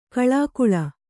♪ kaḷākuḷa